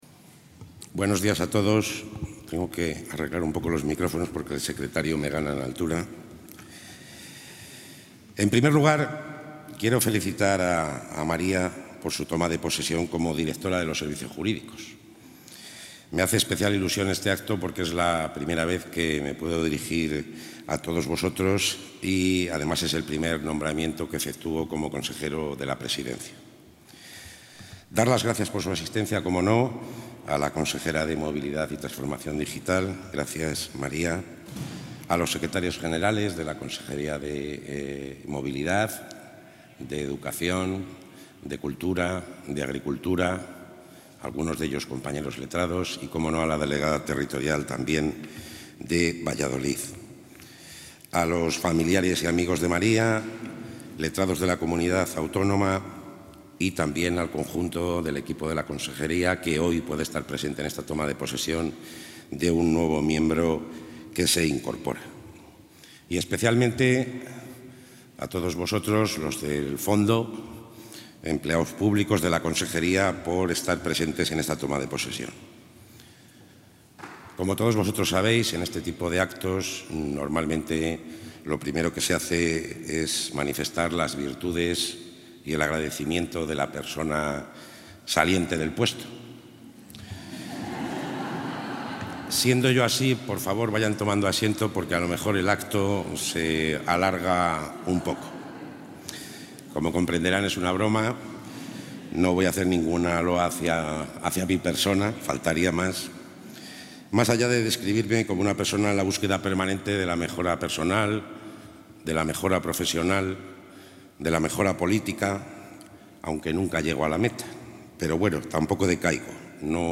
Intervención del consejero de la Presidencia.
El consejero de la Presidencia, Luis Miguel González Gago, ha presidido esta mañana en Valladolid la toma de posesión de la nueva directora de los Servicios Jurídicos de la Junta, María García Fonseca.